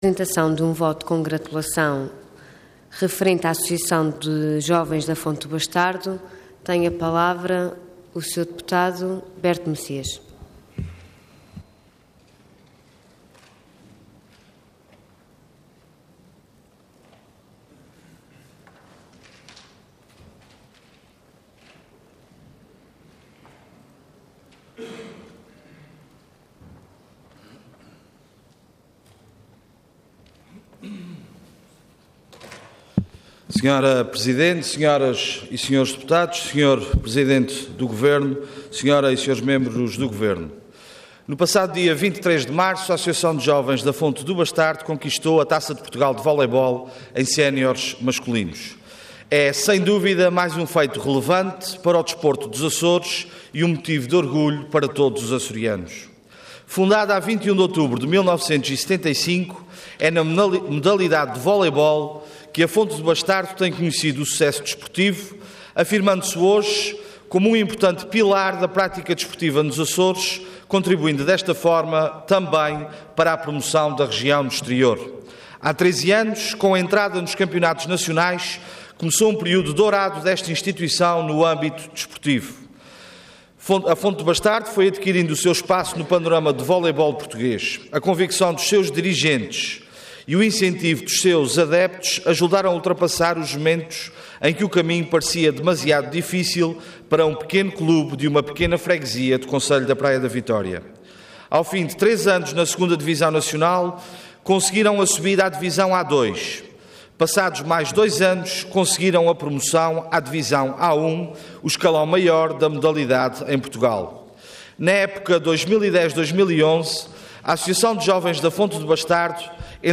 Website da Assembleia Legislativa da Região Autónoma dos Açores
Intervenção Voto de Congratulação Orador Berto Messias Cargo Deputado Entidade PS